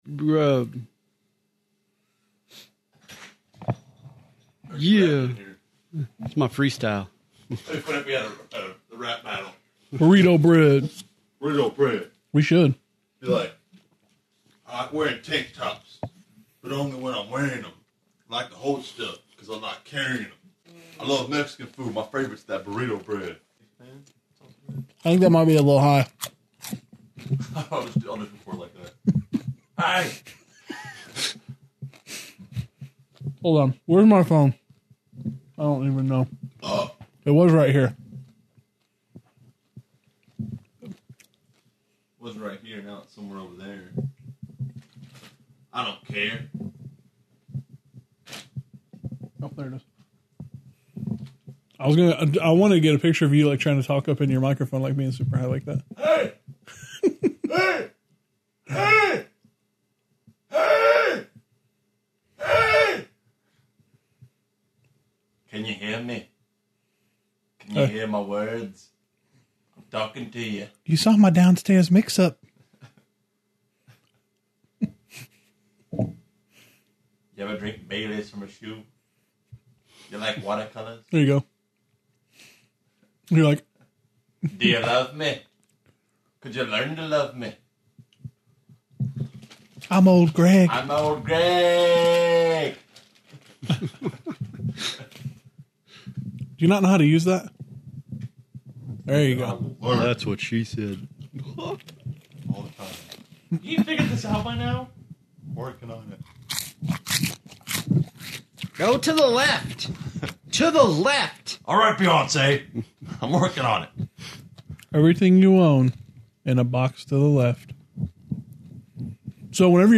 After a quick run to the convenience store, they are all hyped up on sugar and caffeine. This combination of goofy guys and sugar-laced, caffeinated beverages makes for a very nonsensical conversation comprised of awful singing, more terrible impressions, and an impromptu rap battle.